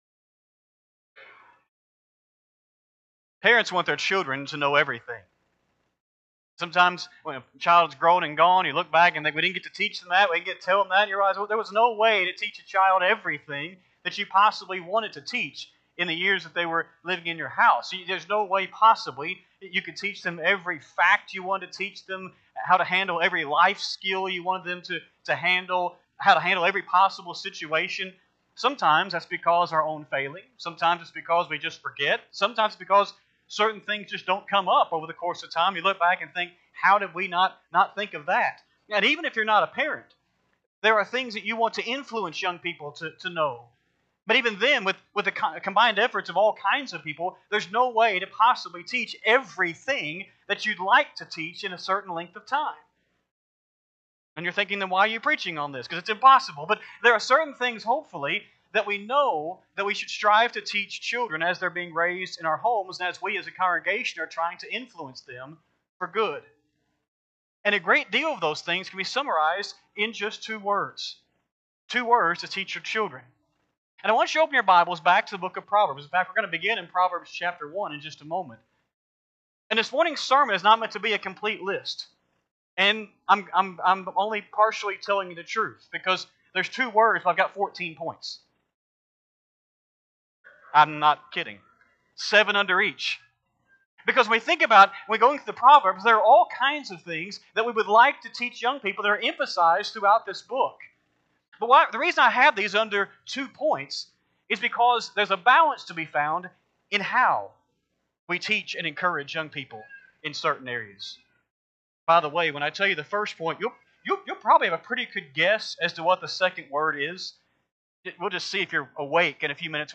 8-10-25-Sunday-AM-Sermon.mp3